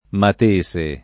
mat%Se] top. m. — altopiano dell’Appennino merid.: Campitello M., San Polo M. (Mol.); San Gregorio M., Piedimonte M. (Camp.); Lago del M. (id.); Monti del M. — sim. l’altro top. Punta M. (Sic.)